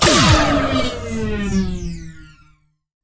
torp.wav